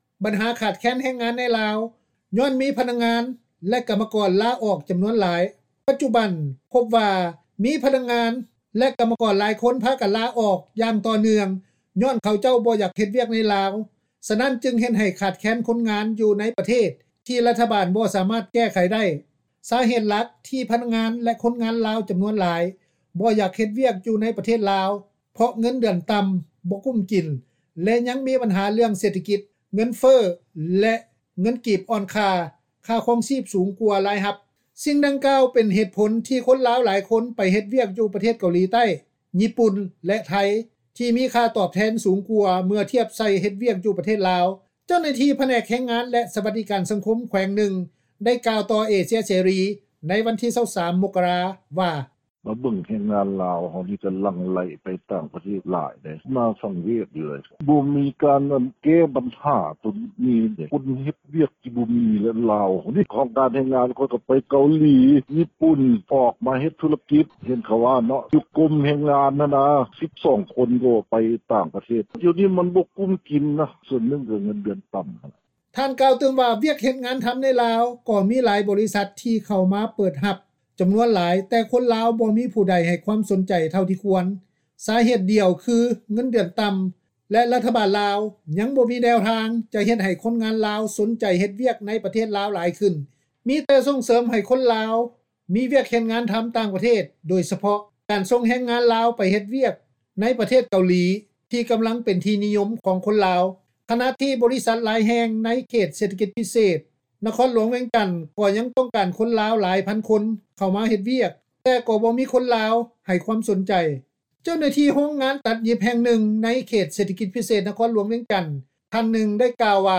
ເຈົ້າໜ້າທີີ່ບໍຣິສັດຈັດຫາງານໃນລາວ ທ່ານນຶ່ງ ກ່າວວ່າ:
ຄົນງານລາວ ທ່ານນຶ່ງ ກ່າວວ່າ:
ຄົນງານລາວ ອີກທ່ານນຶ່ງ ກ່າວວ່າ: